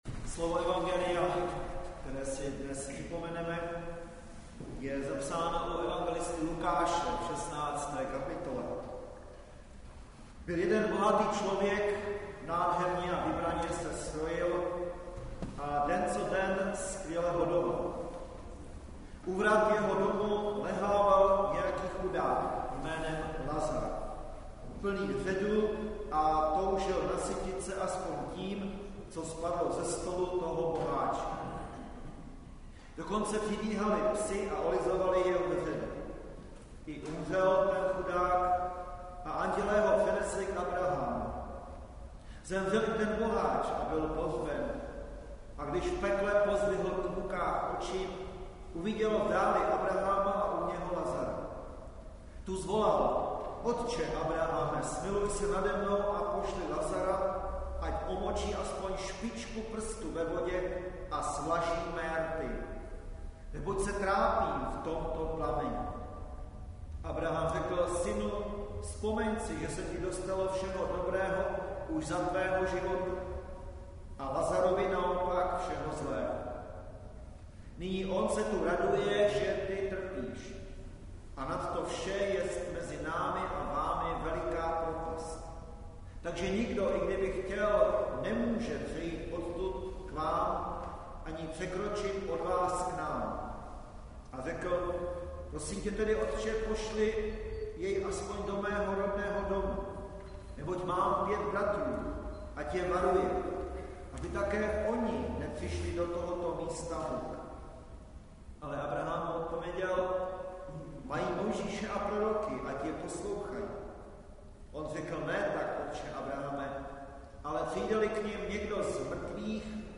záznam kázání